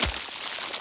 Ice Freeze 2.ogg